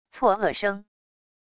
错愕声wav音频